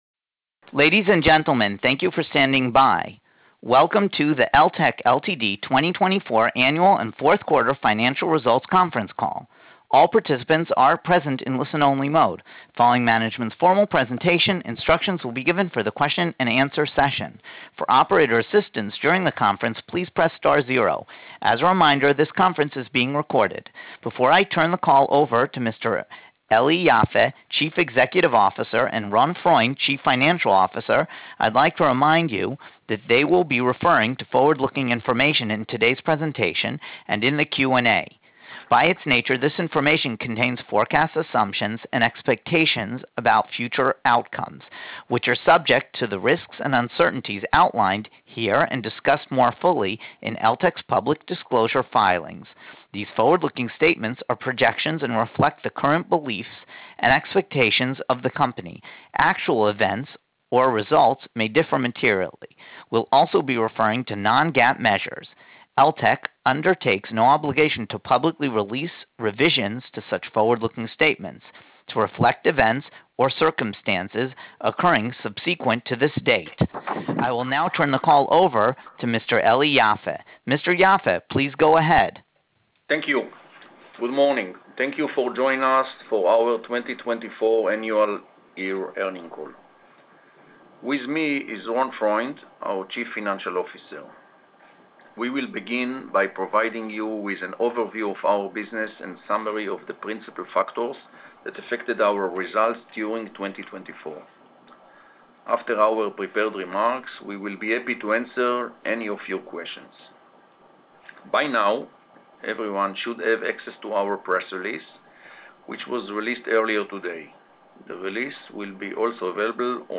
Conference Calls